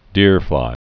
(dîrflī)